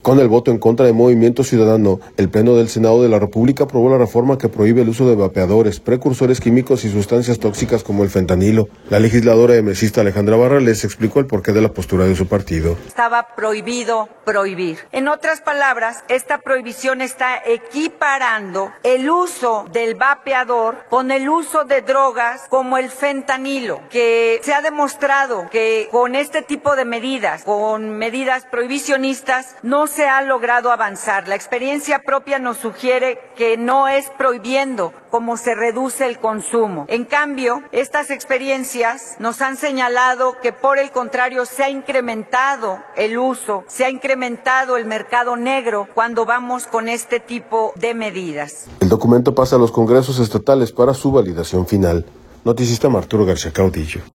Con el voto en contra de Movimiento Ciudadano, el Pleno del Senado de la República aprobó la reforma que prohíbe el uso de vapeadores, precursores químicos y sustancias tóxicas como el fentanilo. La legisladora emecista Alejandra Barrales explicó el por qué de la postura de su partido.